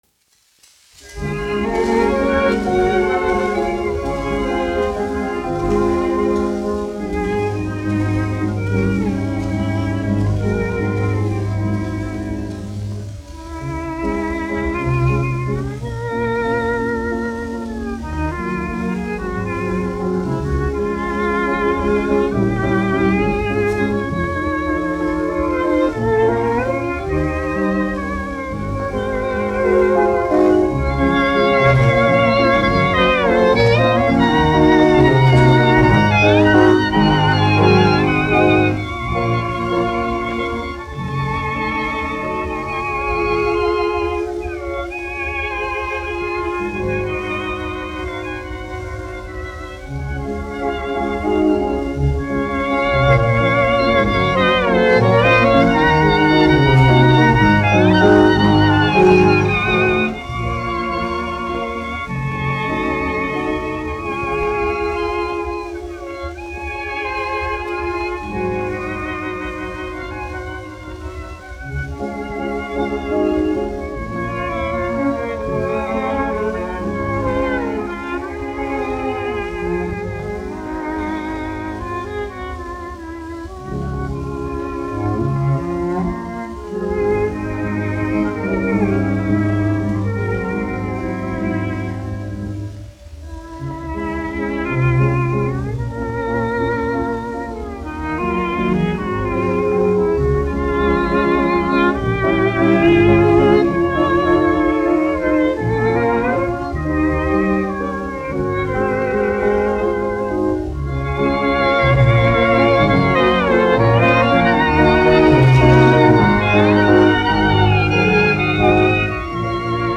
1 skpl. : analogs, 78 apgr/min, mono ; 25 cm
Orķestra mūzika, aranžējumi
Skaņuplate